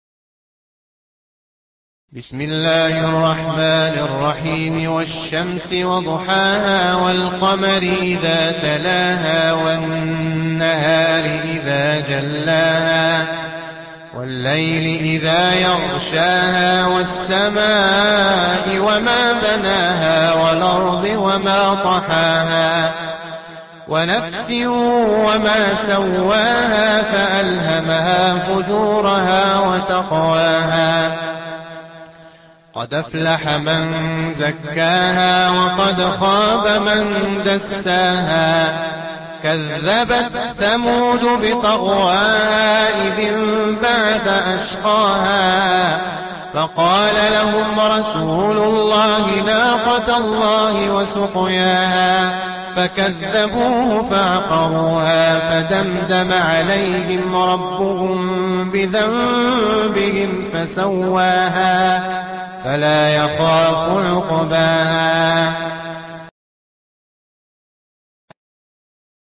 Warch an Nafi Asbahani